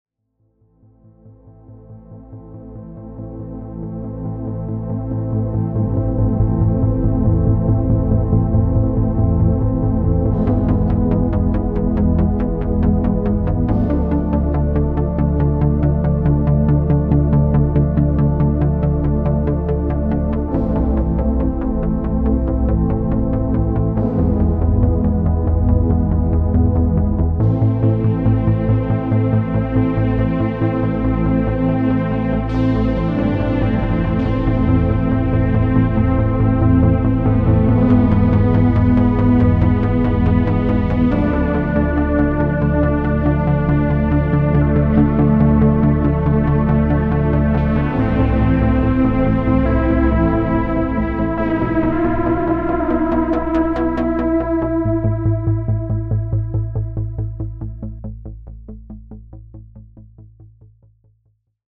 It was recorded in a few hours on this rainy Saturday afternoon. I was inspired by some of the old 80s synth music. The track has a monotonous bass arpeggio to set the rythm. A mellow pad sound sets the somewhat melancholic mood. The synth lead stands out and culminates in a dissonant tone to emphasise the somewhat dystopian feel of the track.